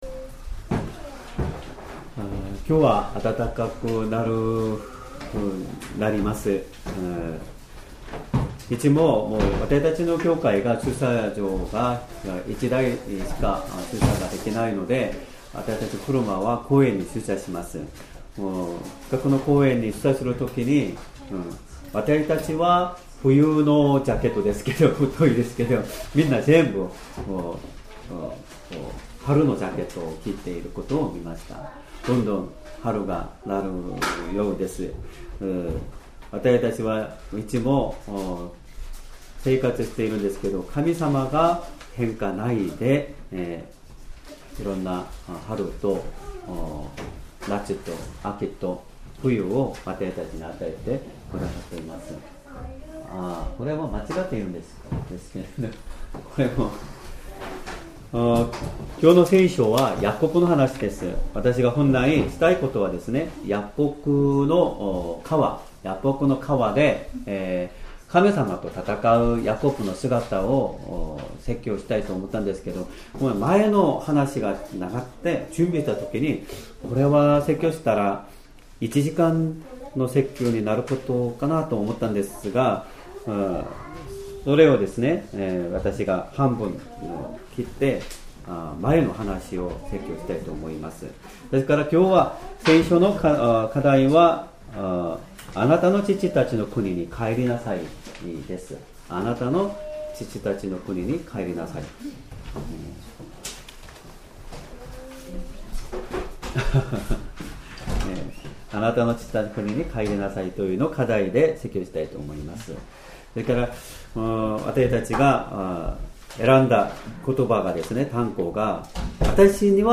Sermon
2026年2月22日 主日礼拝 説教 「イエスの名によって求める祈り」 聖書 創世記31章１-5節 31:1 ところで、ヤコブはラバンの息子たちが、「ヤコブはわれわれの父の物をみな取った。